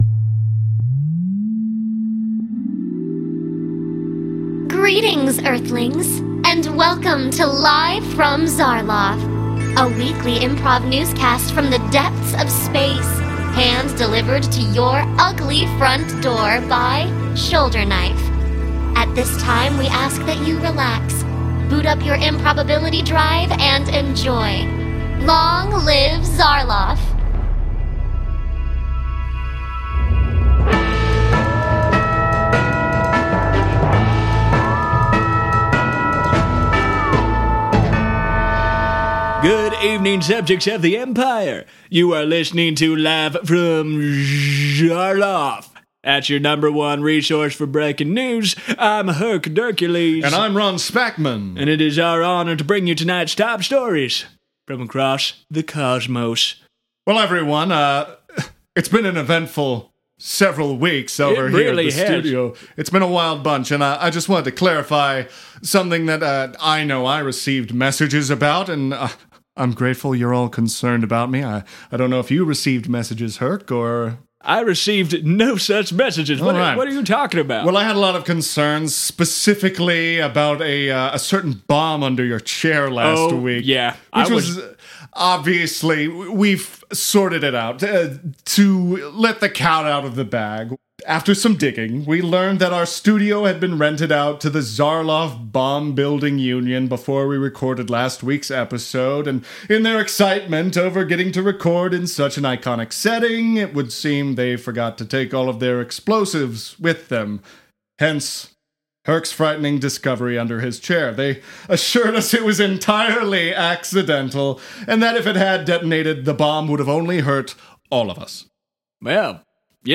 improvised newscast